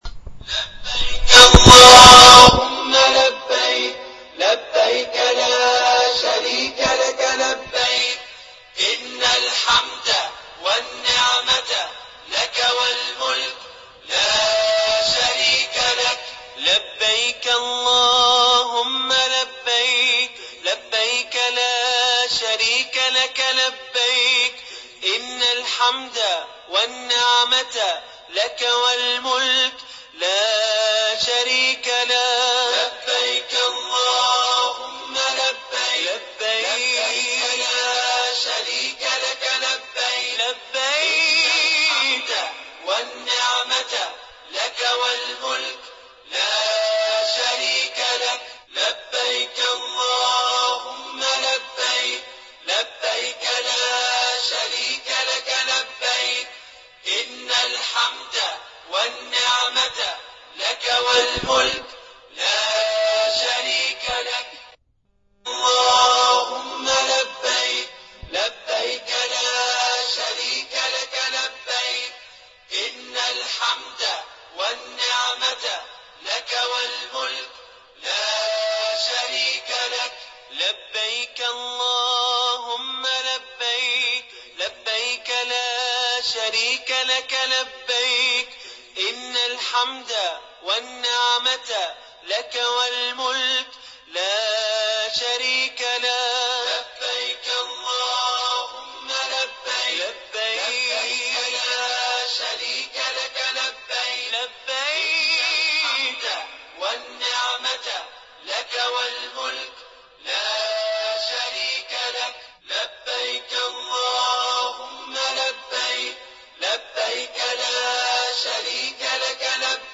As the pilgrims proceed to cast their stones, the air is filled with the sound of “Allahu Akbar” (God is Great), echoing through the valley.